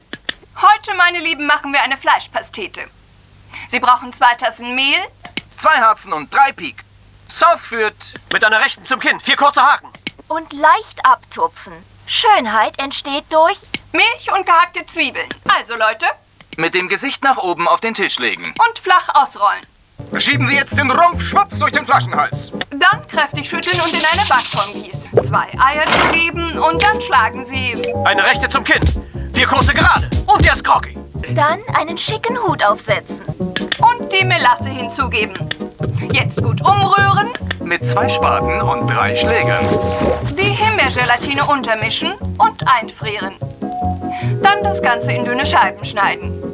Diverse Radio- und Lautsprecherstimmen (alles Neusynchros)
- div. Radiostimmen(8) (Pluto's Dreamhouse) -